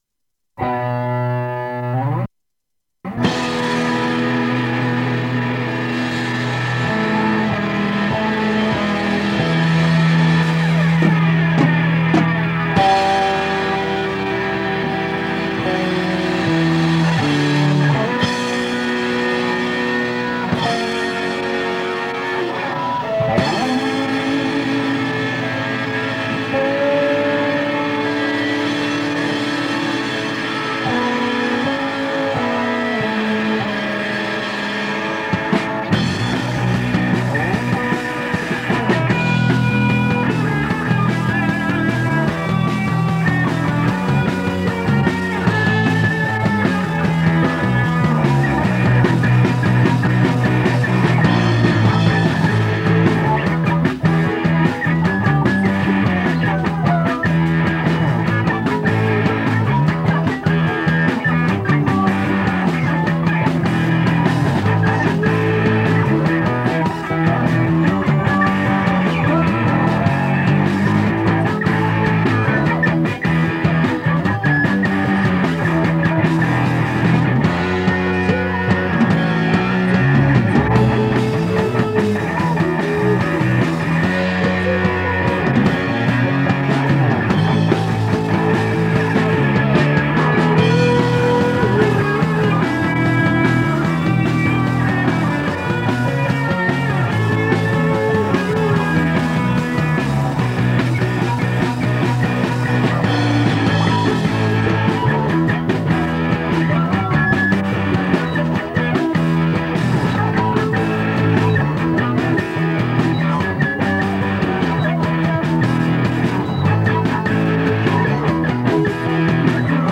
MP3　Data　バンド
◎1985年（大学時代？）バンド練習